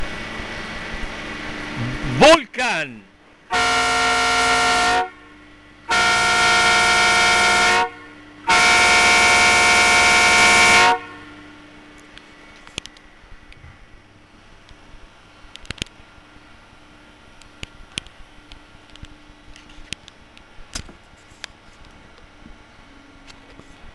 Luchthoorn Vulcan Metal 24V | 146130
Deze luchthoorn werkt op 24V en is uitgevoerd in metaal. Het model heeft een geluidsniveau van 118dB en combineert een hoge toon van 425Hz met een lage toon van 365Hz.